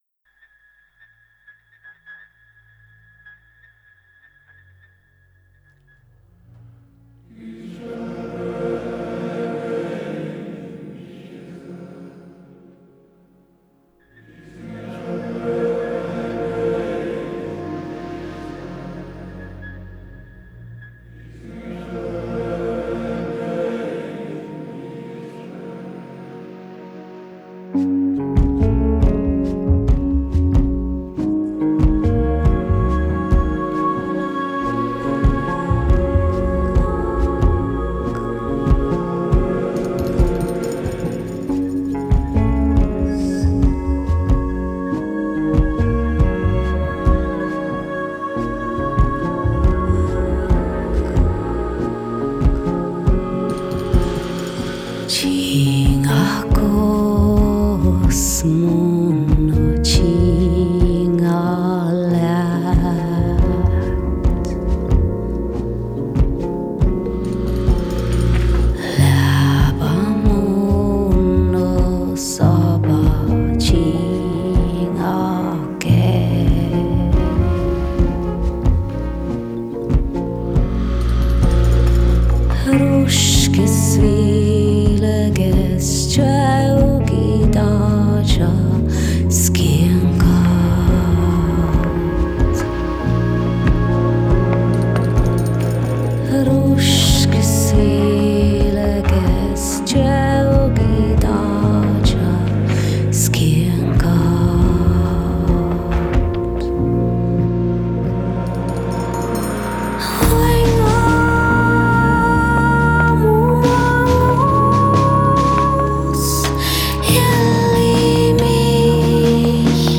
Style: Saami folk, Joik
Stereo